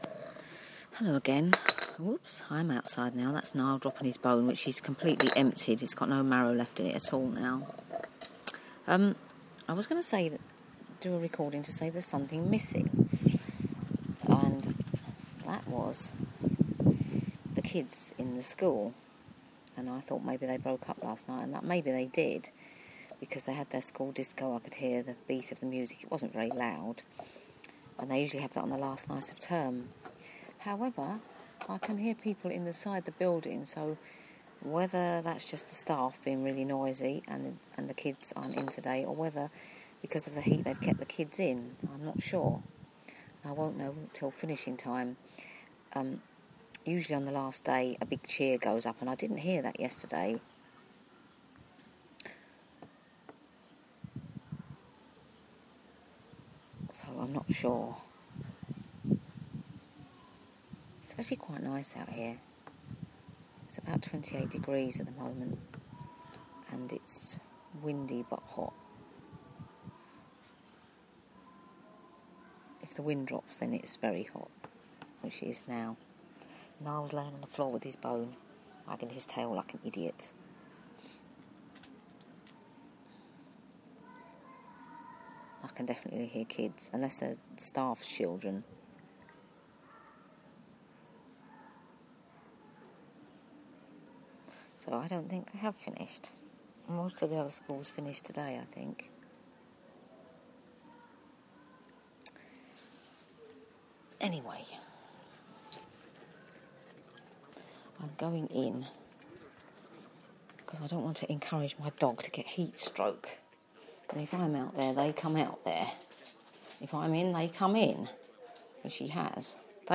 I could not get the hose to work so had to resort to other measures! Oh, the loud noise you hear near the beginning is air in the outside tap, and not anywhere else, lol!!